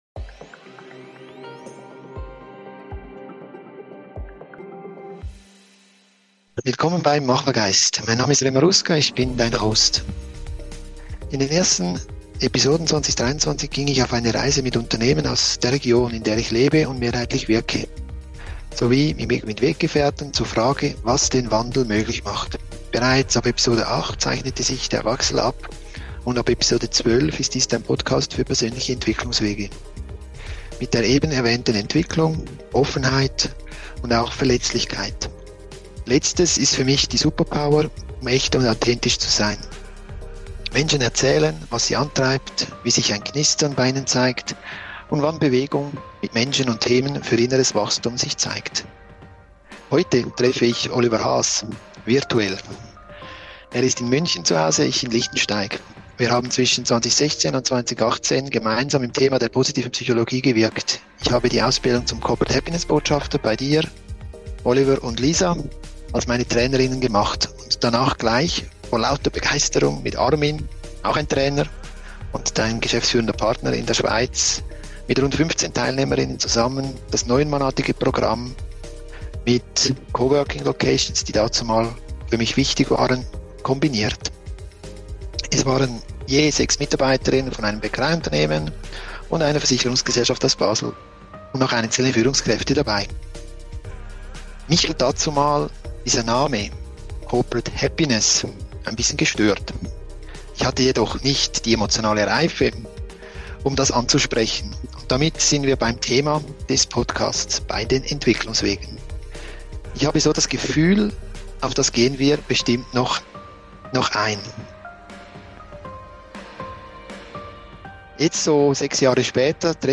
Ein Gespräch über Positivität, (Eigen)Macht, Schatten und Führung